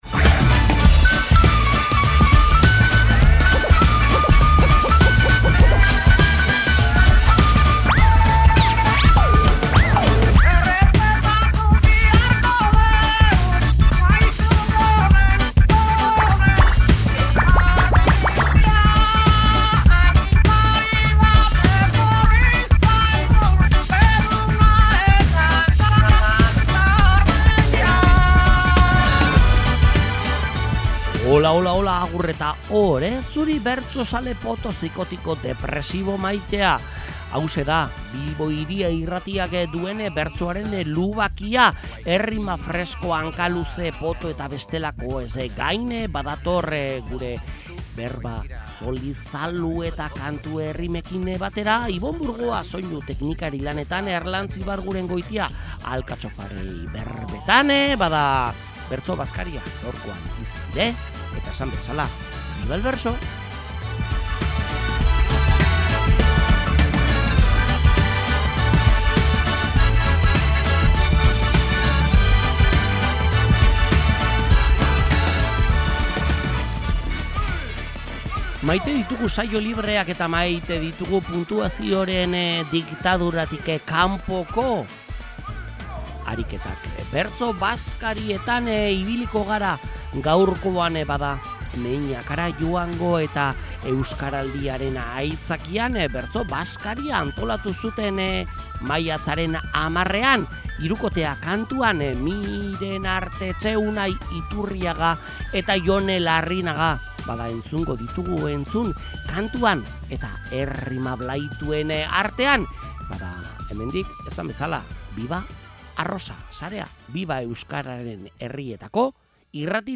Bertso-bazkarien grazia zabaldu dugu Bilbo Hiria irratiaren POTTO saioan. Maiatzaren 10ean, Euskaraldia aitzakiatzat hartuta, bertso-bazkaria izan zen Meñakan.